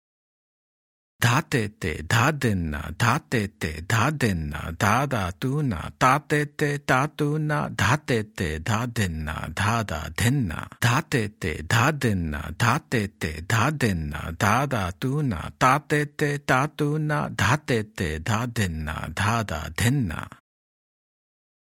Example 6 – Spoken Twice